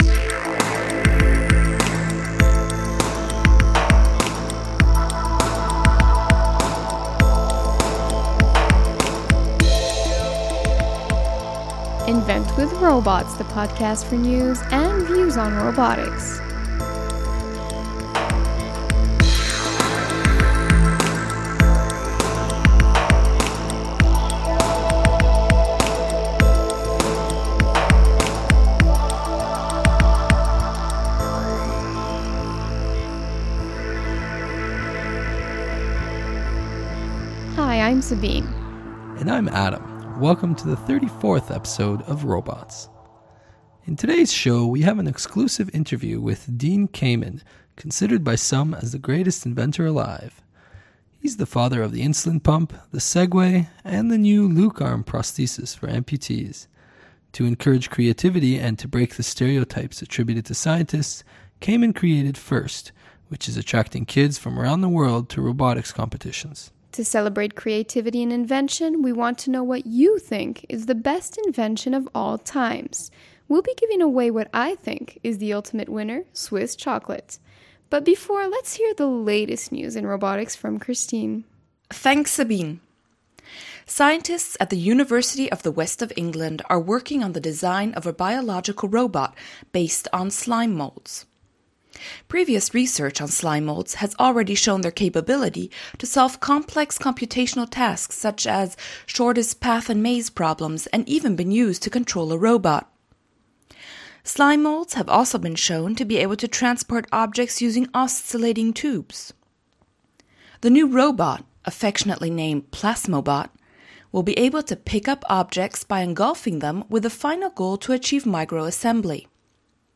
In this episode we have an exclusive interview with Dean Kamen, considered by some as the greatest inventor alive.